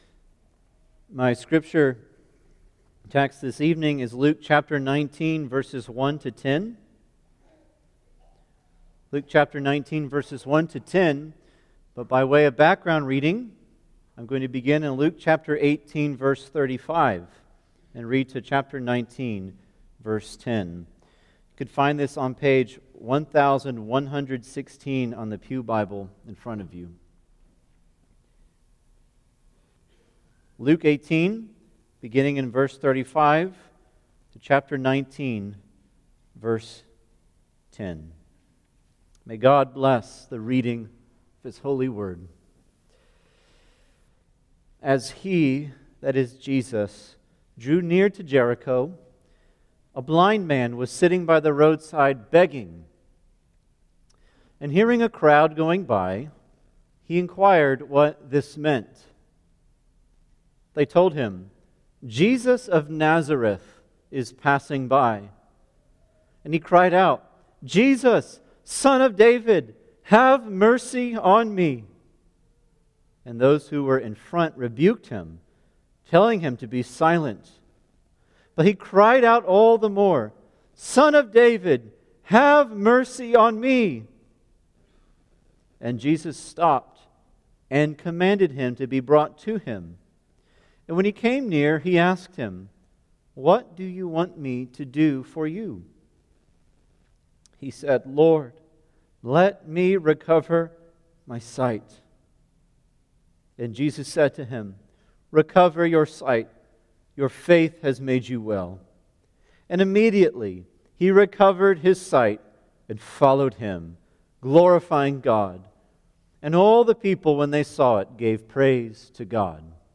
Salvation Series Various Sermons Book Luke Watch Listen Save In Luke 19:1-10, we witness Jesus reaching out to save sinners, even the tax collectors and despised of society.